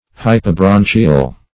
Search Result for " hypobranchial" : The Collaborative International Dictionary of English v.0.48: Hypobranchial \Hy`po*bran"chi*al\, a. [Pref. hypo- + branchial.]